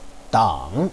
dang3.wav